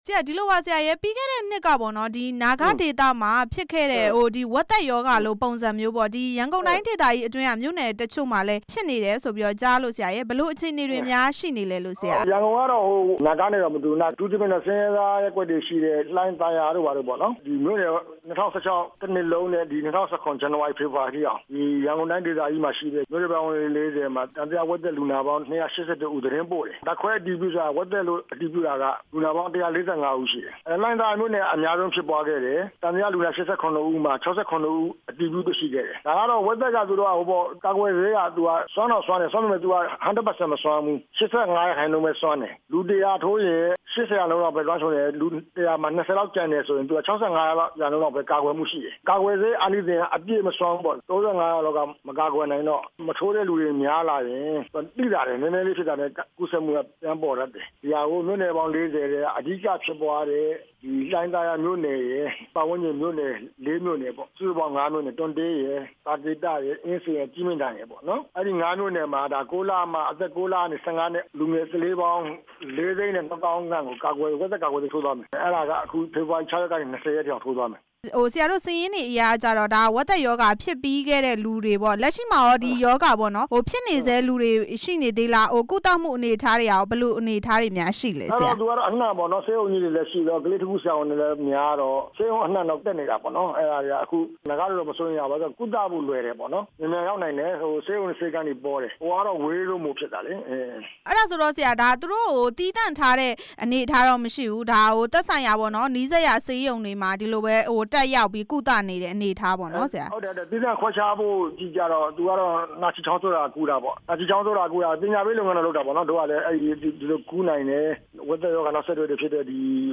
ဝက်သက်ရောဂါ ကာကွယ်ဆေးထိုးမယ့် အကြောင်း မေးမြန်းချက်